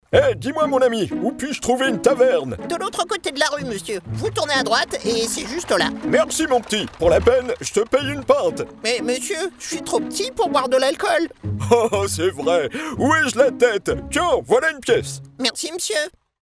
Microphone NeumannTLM 103
CARTOON
2 personnages